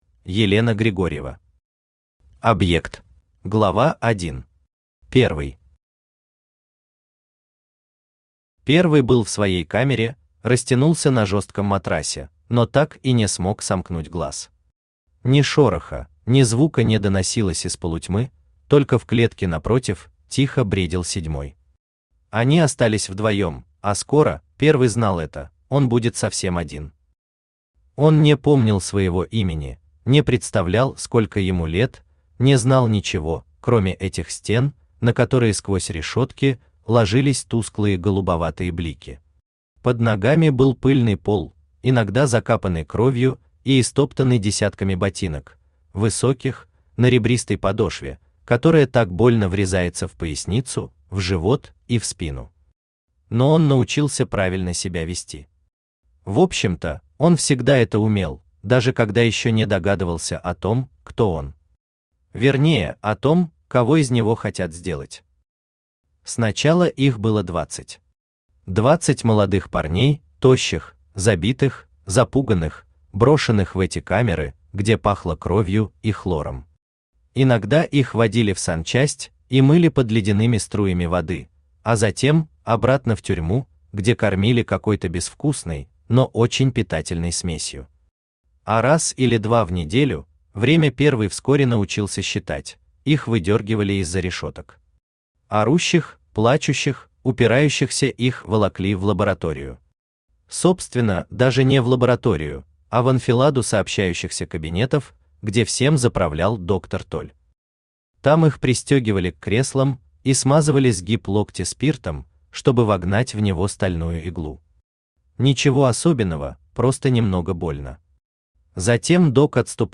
Аудиокнига Объект | Библиотека аудиокниг
Aудиокнига Объект Автор Елена Григорьева Читает аудиокнигу Авточтец ЛитРес.